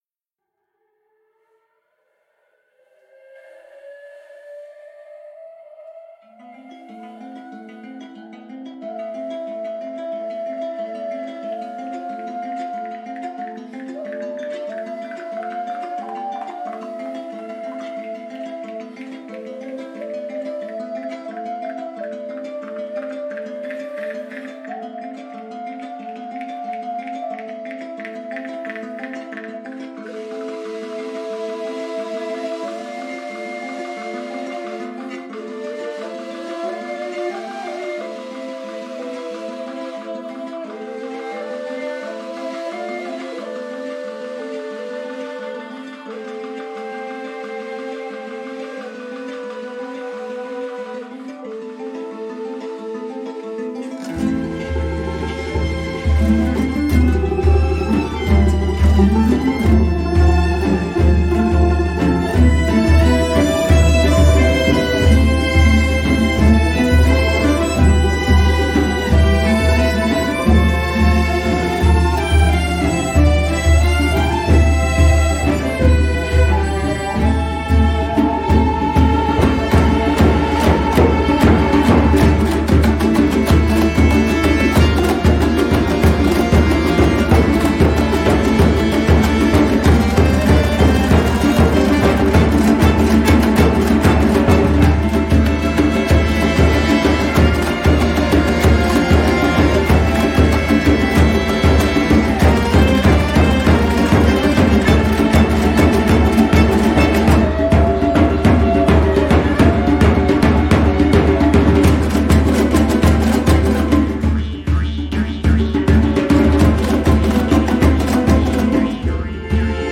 ұлт аспаптар ансамбльдеріне арналған шығармалары